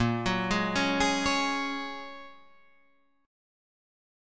A#m7#5 Chord